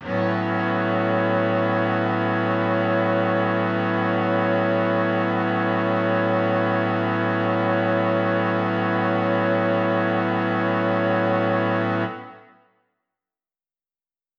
SO_KTron-Cello-A6:9.wav